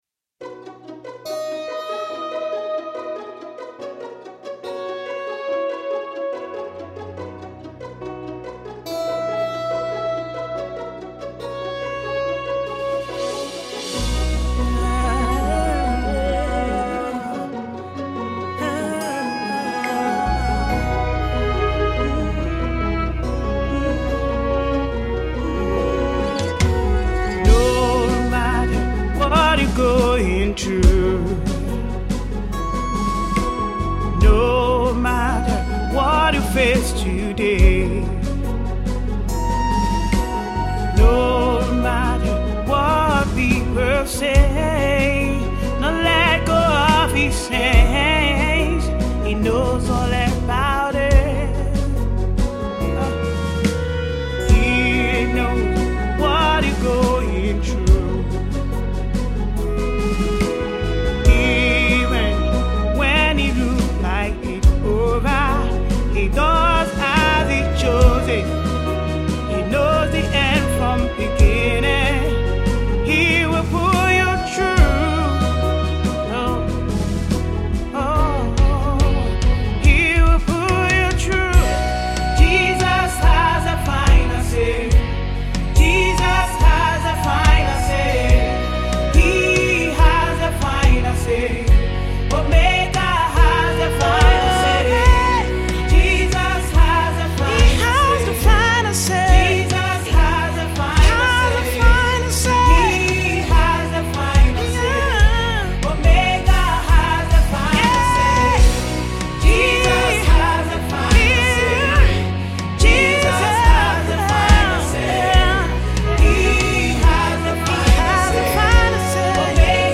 Nigerian songstress and praise/worship leader